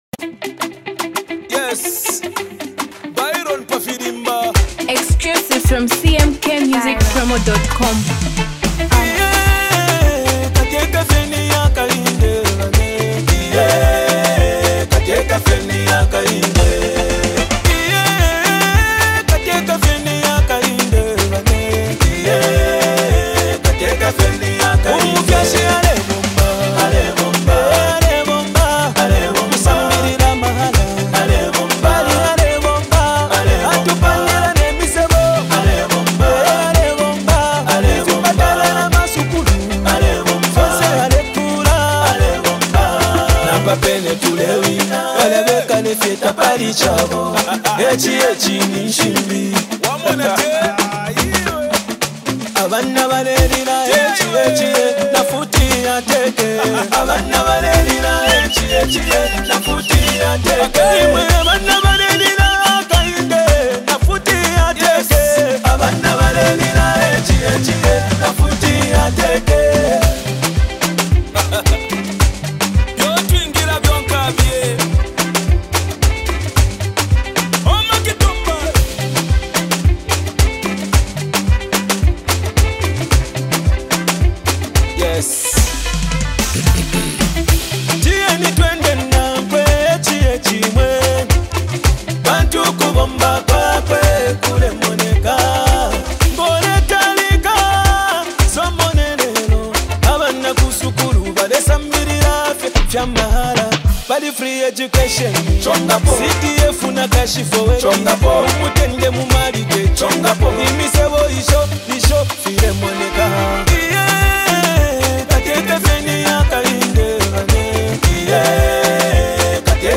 Campaign song